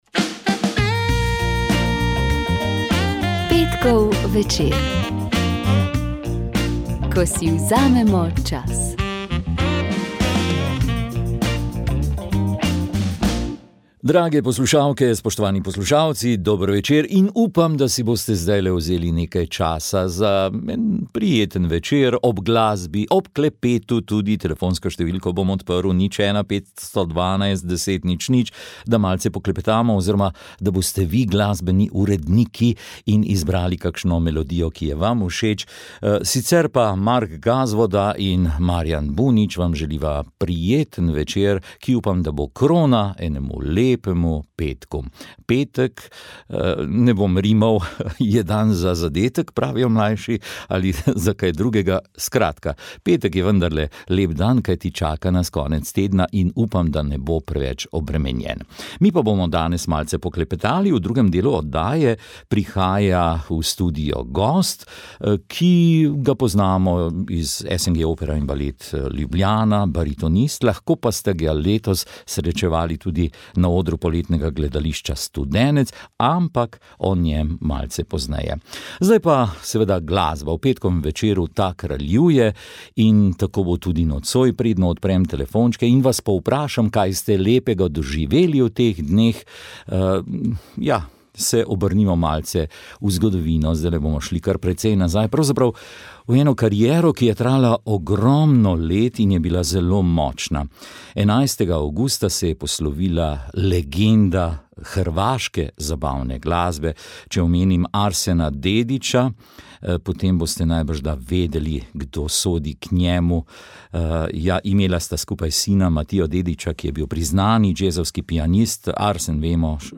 Podelila sta svoja doživetja, spregovorila tudi o pripravah za Stično - festival verne mladine tretjo septembrsko soboto, ter ob sklepu povabila že na novoletno evropsko srečanje z brati iz Taizeja, ki bo po skoraj pol stoletja tako kot prvo letos znova v Parizu.